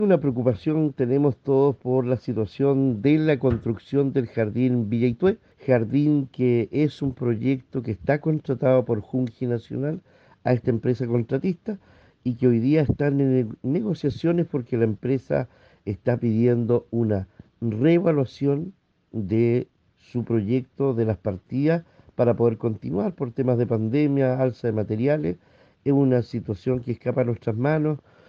El alcalde Cristian Ojeda, manifestó su preocupación por lo que acontece, entregando además algunos detalles administrativos del conflicto.